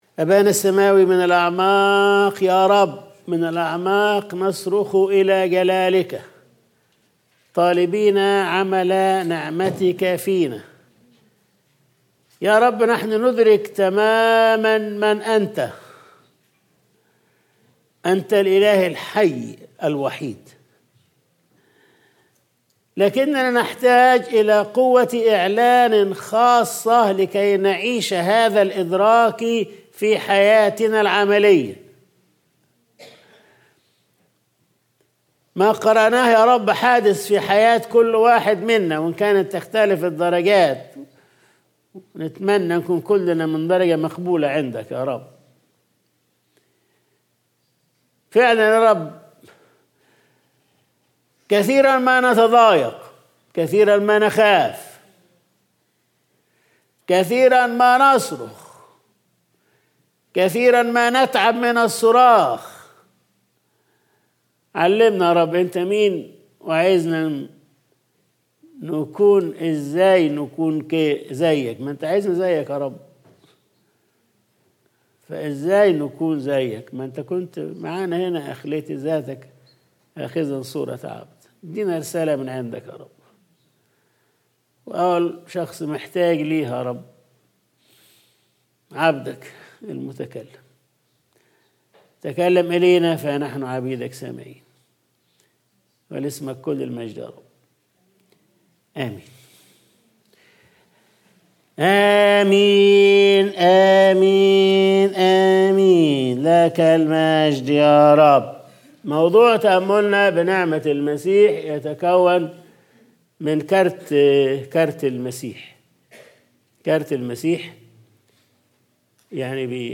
Sunday Service | أَنَا أَنَا .. مَنْ أَنْتِ